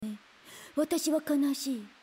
A clip from the mobile game Fate/Grand Order featuring the character Kiyohime in a learning moment.